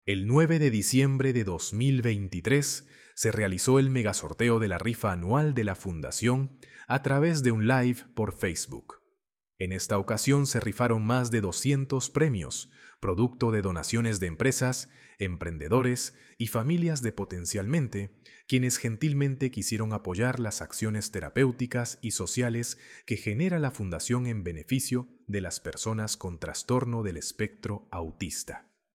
El 9 de diciembre de 2023, se realizó el mega sorteo de la Rifa Anual de la Fundación, a través de un live por Facebook.